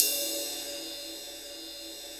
RIDE19.wav